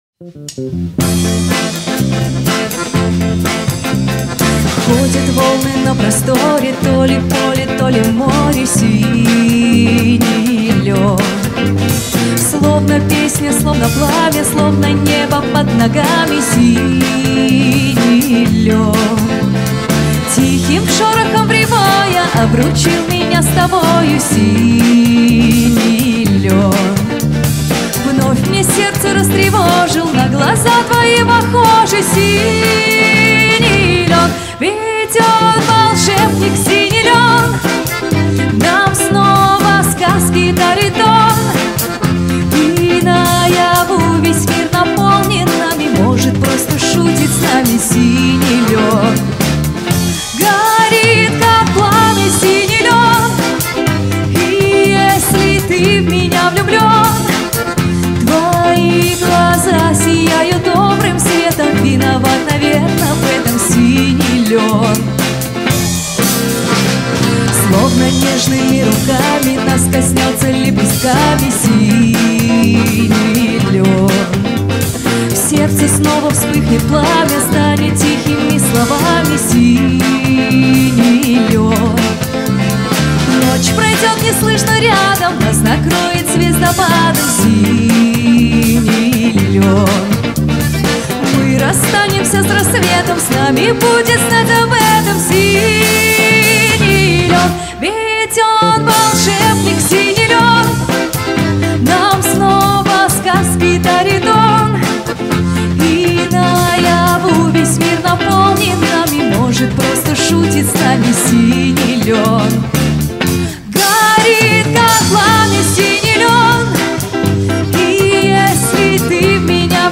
исполняет шлягеры советской эстрады 50-70х. годов.
аккордеон
бас гитара
барабаны